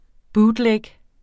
Udtale [ ˈbuːdˌlεg ]